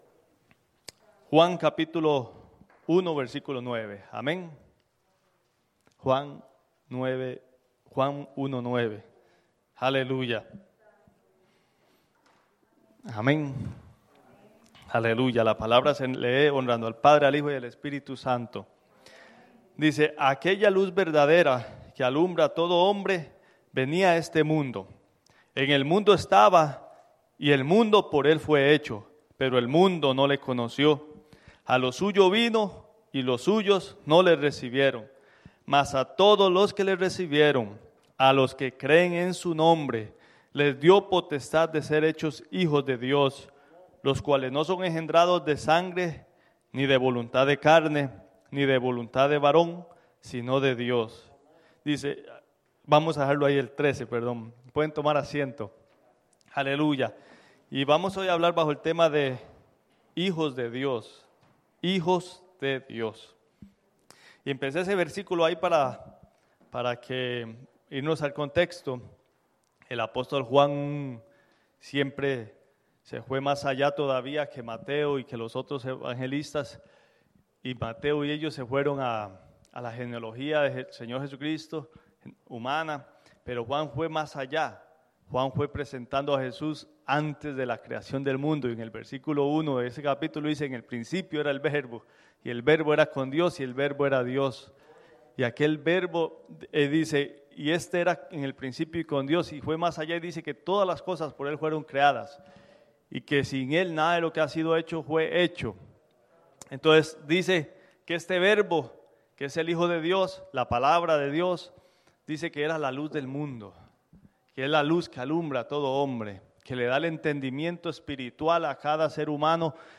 Iglesia Misión Evangélica
Hijos De Dios | Predica